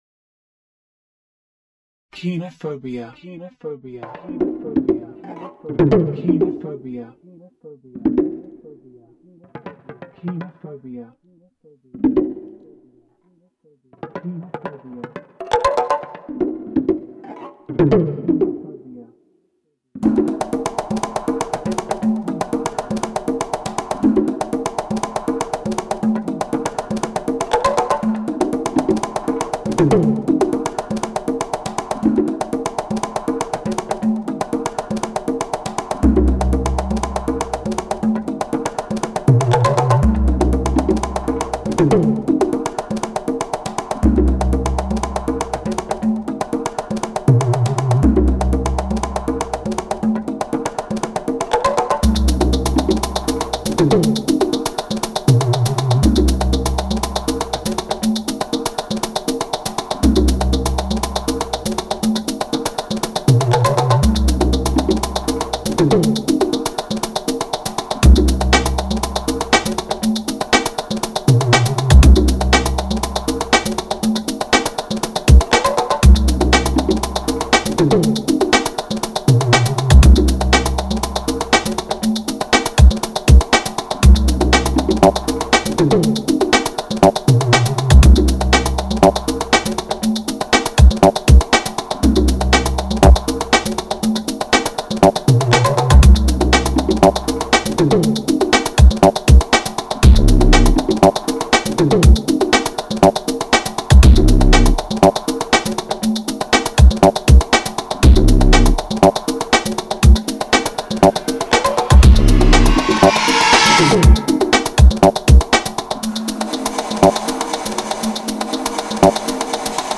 New samples near the end to make it even weirder.
Techno